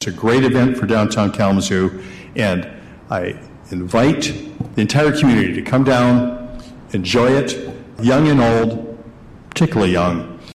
Mayor Dave Anderson says it’s the 62nd year for the event, which features floats, fire trucks, bands and of course the big balloons.